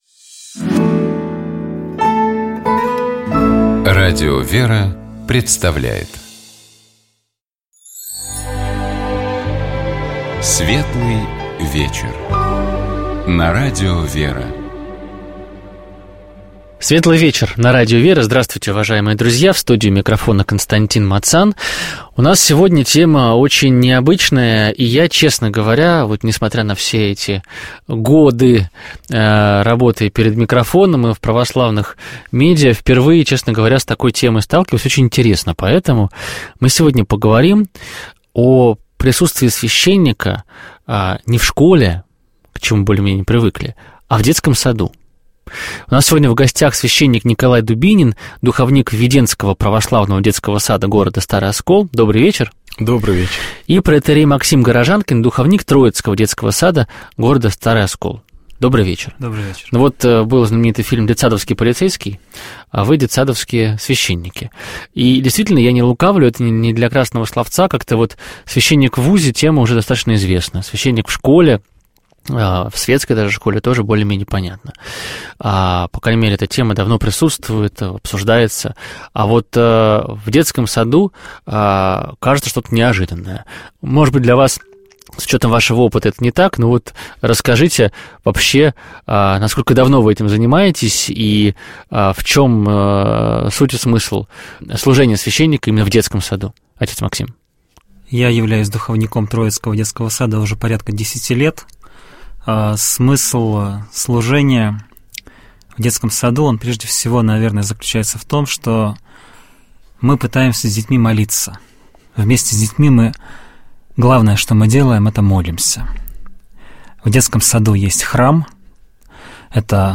Мы говорили о роли священника в детских садах, об особенностях и значении детской Литургии и о том, как можно доносить до детей в доступной для них форме важные духовные истины. Ведущий: